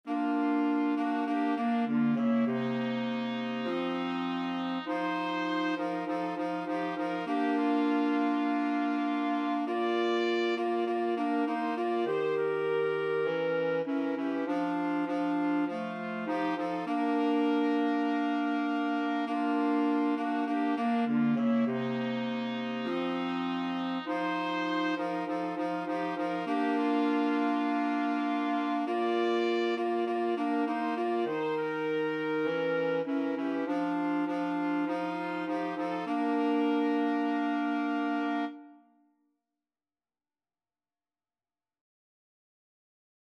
Trumpet
Clarinet
Tenor Saxophone
4/4 (View more 4/4 Music)